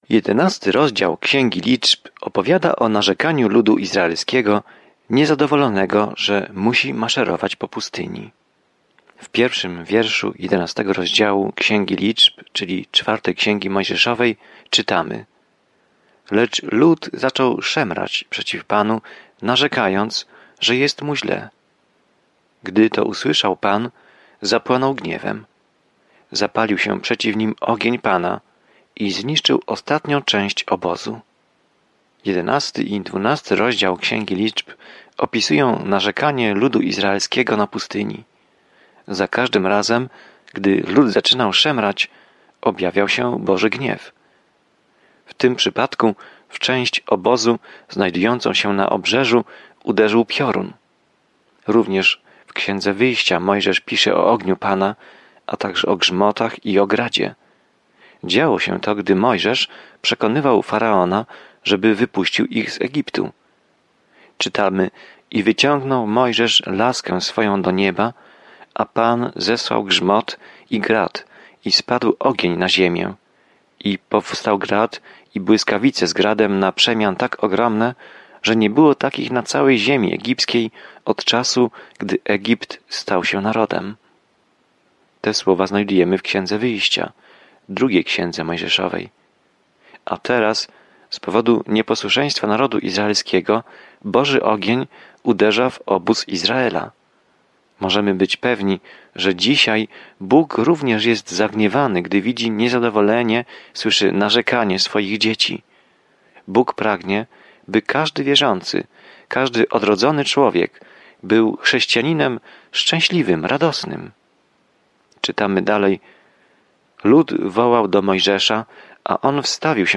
Pismo Święte Liczb 11 Dzień 7 Rozpocznij ten plan Dzień 9 O tym planie W Księdze Liczb spacerujemy, wędrujemy i oddajemy cześć Izraelowi przez 40 lat na pustyni. Codziennie podróżuj po Liczbach, słuchając studium audio i czytając wybrane wersety słowa Bożego.